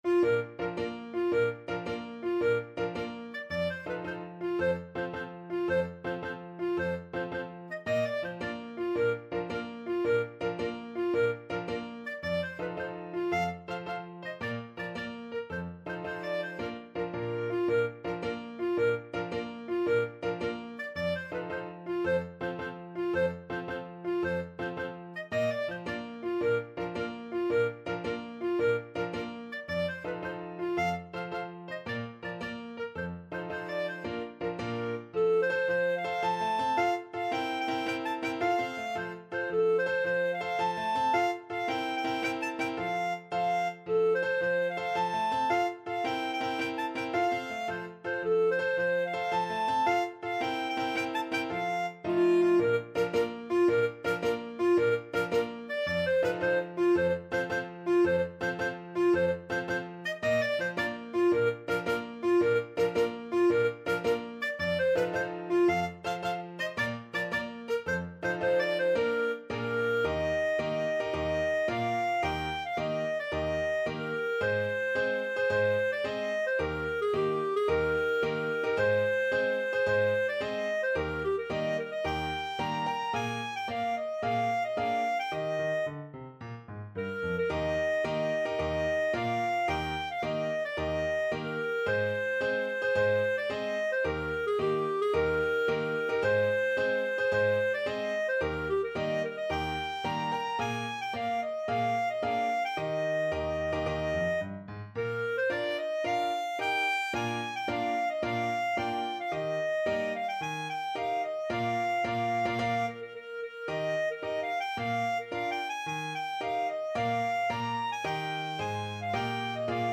Free Sheet music for Clarinet
Clarinet
Bb major (Sounding Pitch) C major (Clarinet in Bb) (View more Bb major Music for Clarinet )
6/8 (View more 6/8 Music)
Classical (View more Classical Clarinet Music)